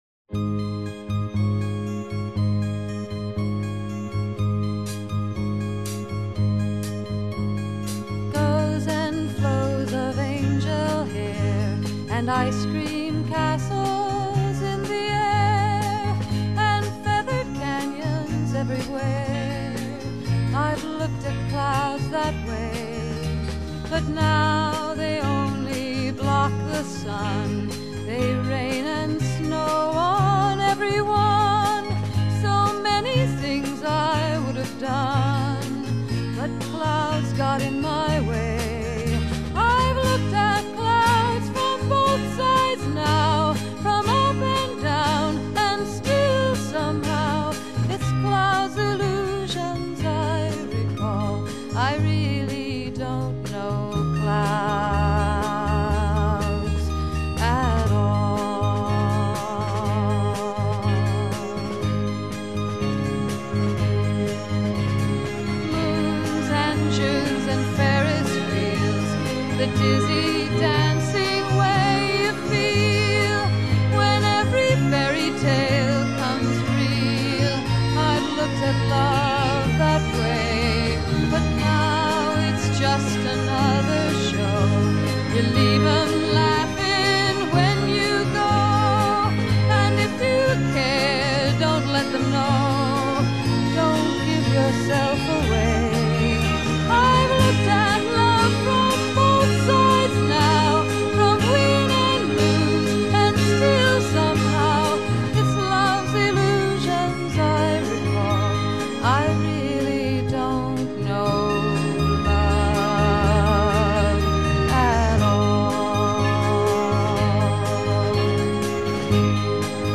Folk/Folk-Rock | EAC Rip | Flac(Image) + Cue + Log / BaiDu
她的嗓音清亮透明，充滿女性純真的柔美感性，每一首歌經過她的詮釋，都變得具有淨化心靈的神奇效果。